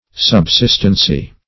Subsistency \Sub*sist"en*cy\, n.